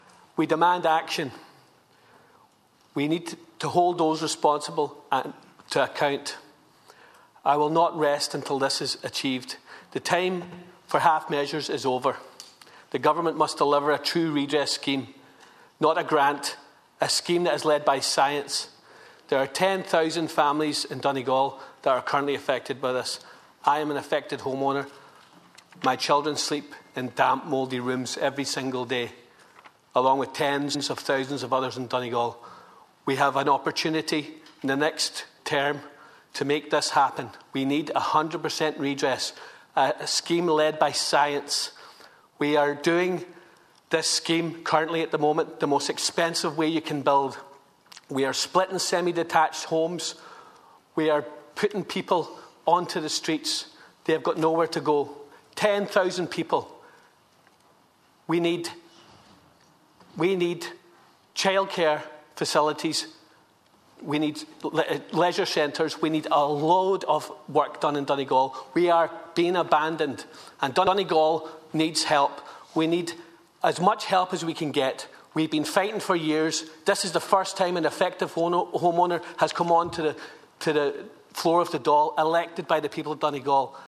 Deputy Charles Ward speaks in the Dail for the first time
Donegal Deputy Charles Ward of the 100% Redress Party has spoken in the Dail for the first time this evening.